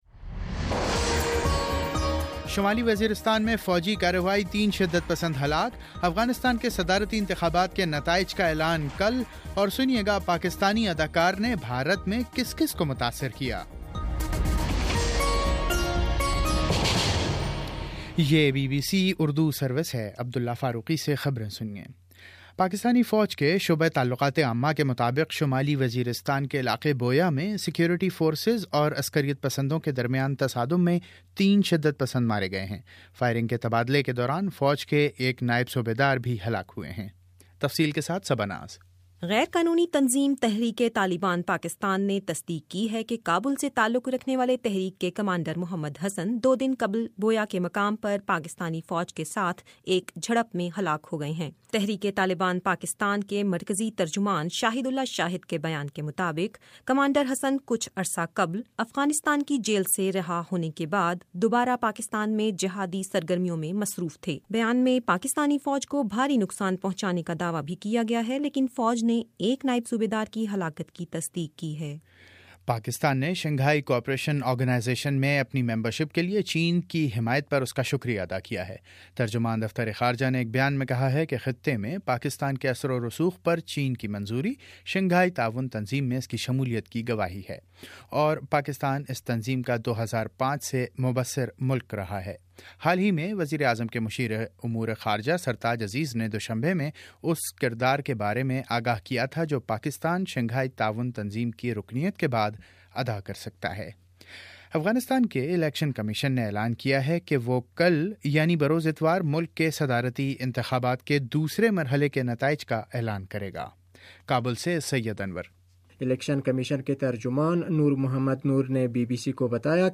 ستمبر20 : شام چھ بجے کا نیوز بُلیٹن سات
دس منٹ کا نیوز بُلیٹن روزانہ پاکستانی وقت کے مطابق صبح 9 بجے، شام 6 بجے اور پھر 7 بجے۔